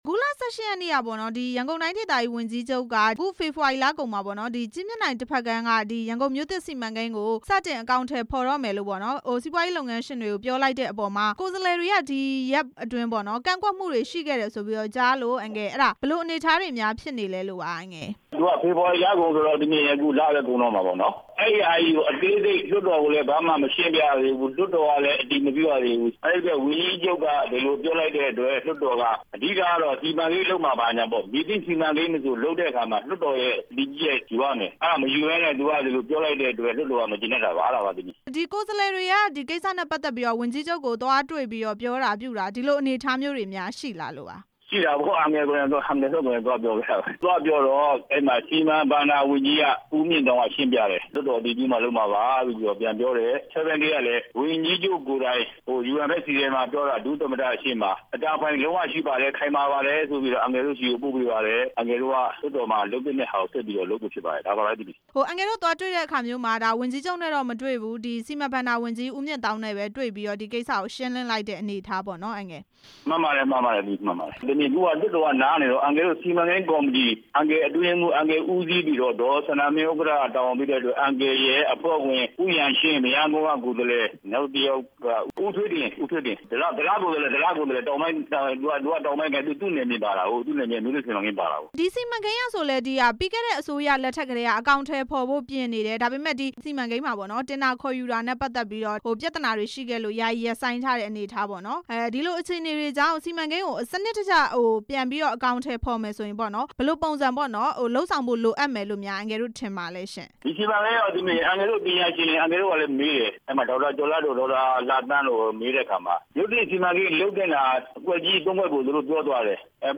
ရန်ကုန်မြို့သစ် စီမံကိန်း ပြန်လည်စတင်မယ့် အစီအစဉ် မေးမြန်းချက်